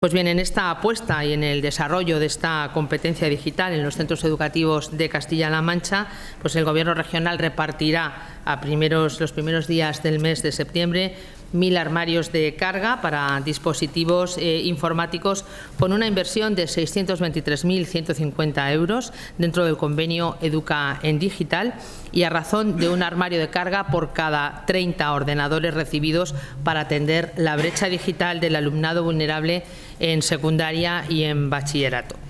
Así lo ha dado a conocer Rodríguez en una rueda de prensa celebrada en los servicios centrales de la Consejería, en la que ha estado acompañada del viceconsejero de Educación, Amador Pastor, y de la secretaria general de la Consejería, Inmaculada Fernández.